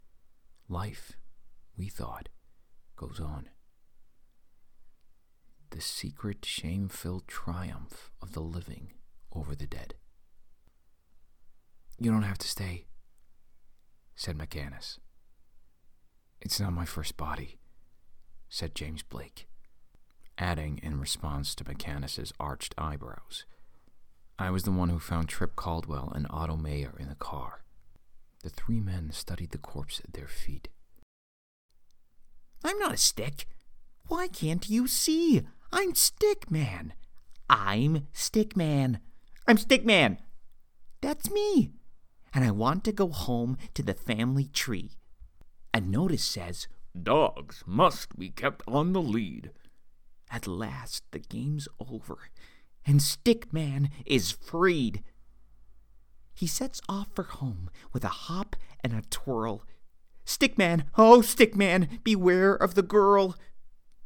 US Reel
• Home Studio